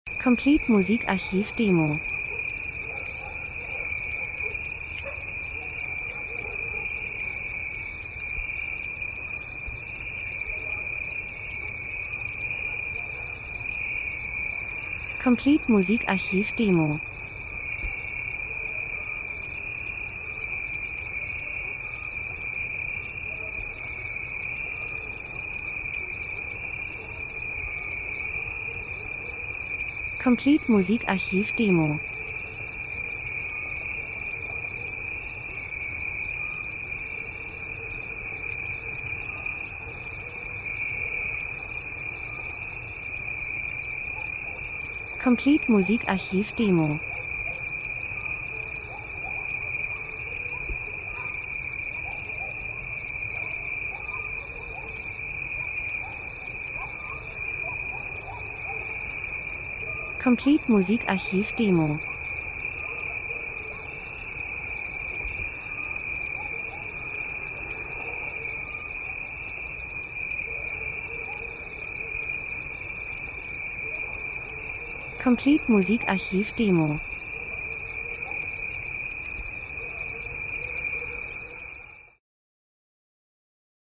Sommer -Geräusche Soundeffekt Natur Grillen Hitze 01:23